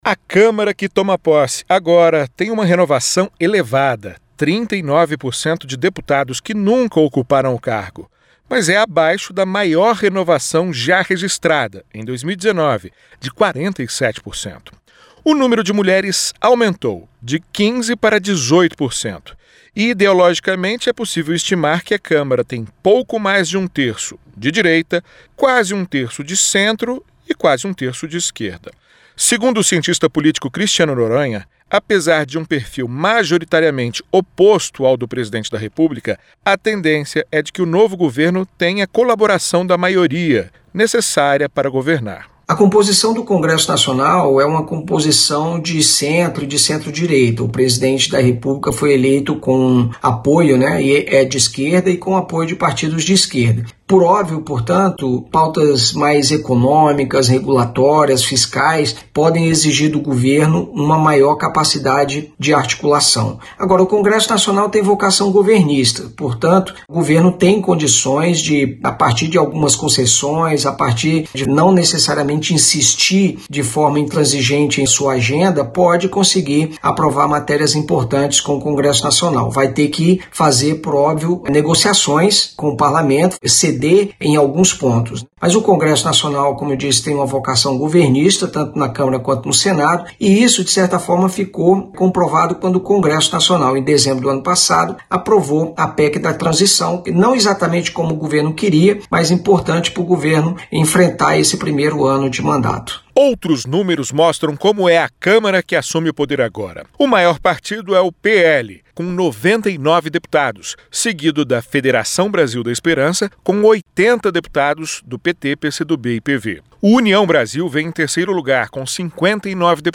Dados do perfil dos deputados eleitos são divulgados - Radioagência